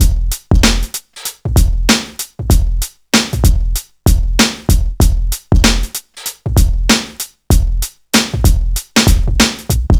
Free drum loop sample - kick tuned to the F note. Loudest frequency: 1651Hz
• 96 Bpm Drum Beat F Key.wav
96-bpm-drum-beat-f-key-EUo.wav